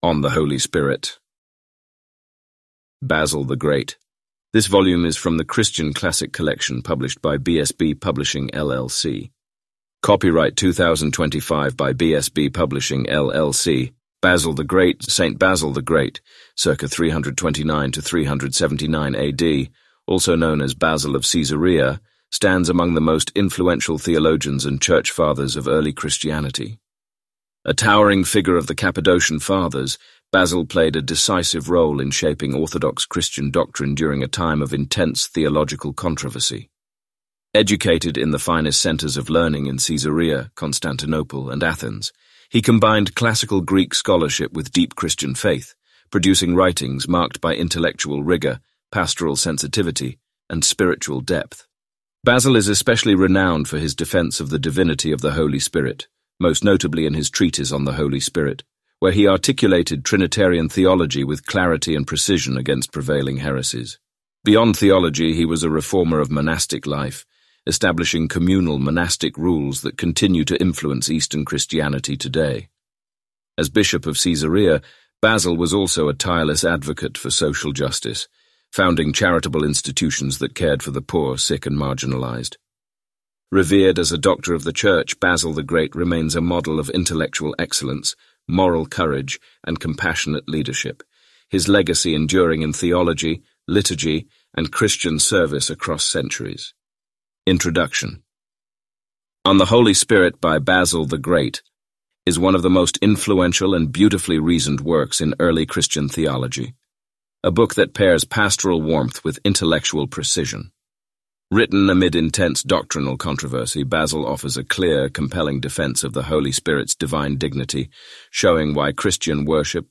Complete Audiobook Play Download Individual Sections Listening Tips Download the MP3 files and play them using the default audio player on your phone or computer.